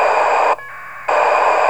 Начало » Записи » Радиоcигналы классифицированные
APRS 144.800 FM 21-22